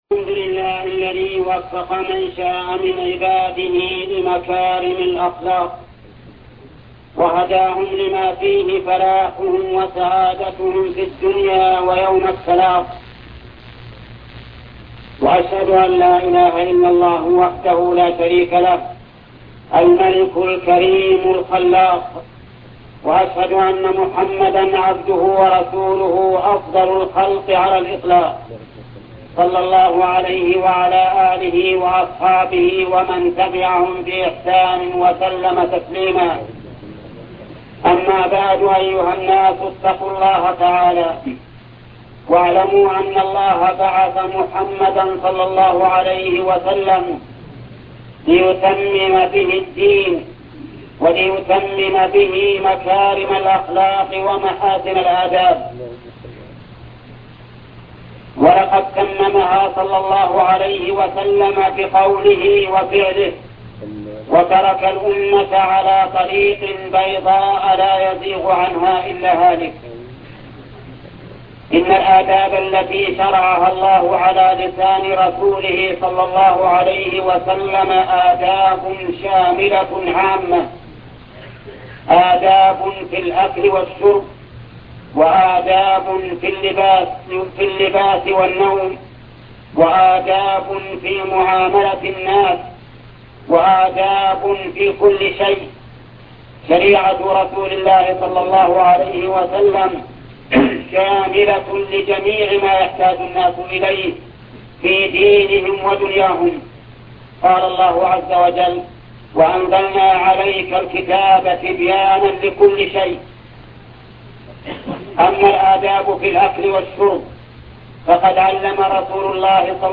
خطبة آداب إسلامية الشيخ محمد بن صالح العثيمين